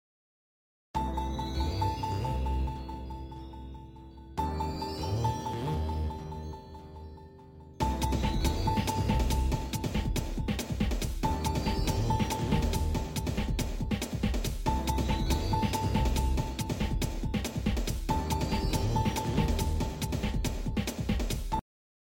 Nintendo DS Esque, Very Retro, Sound Effects Free Download